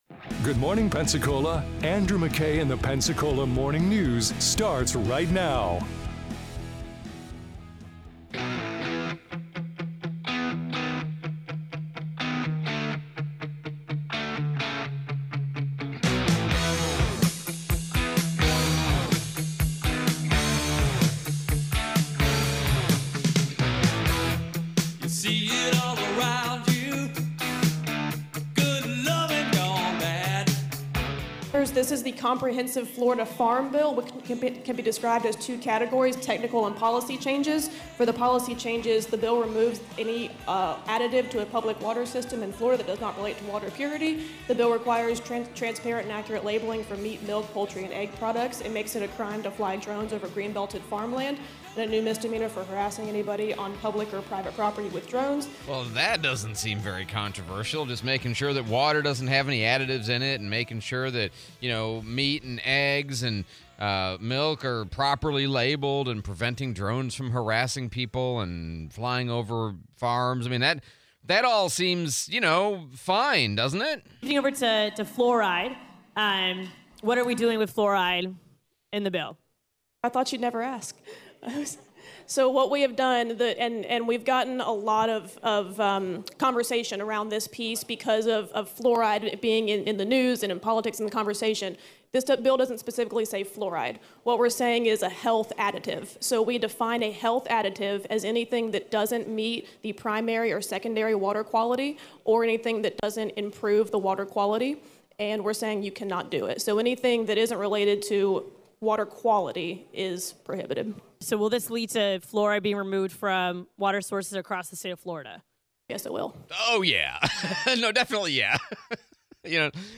Fluoride in water, replay of Pensacola Mayor DC Reeves interview